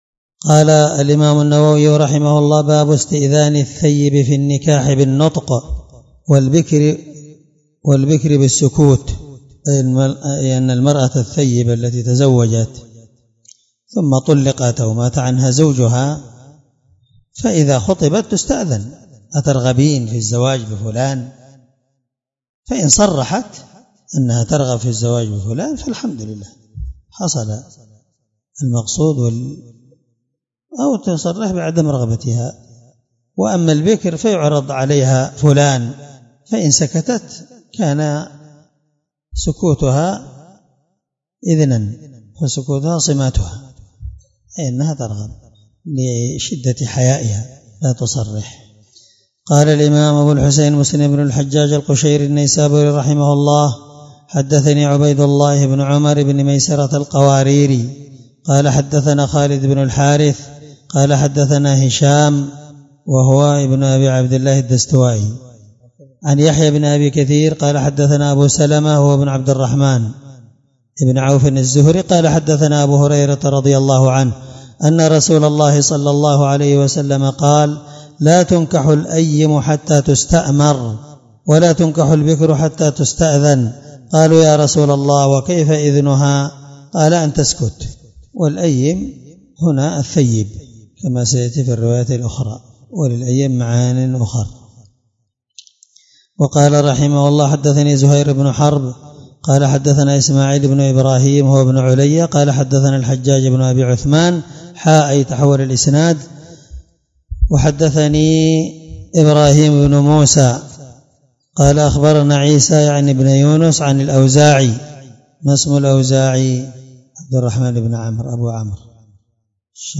الدرس16من شرح كتاب النكاح حديث رقم(1419-1421) من صحيح مسلم